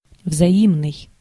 Ääntäminen
Ääntäminen France Tuntematon aksentti: IPA: /ʁe.si.pʁɔk/ Haettu sana löytyi näillä lähdekielillä: ranska Käännös Ääninäyte Adjektiivit 1. взаимный (vzaimnyi) Muut/tuntemattomat 2. обоюдный (obojudnyi) Suku: f .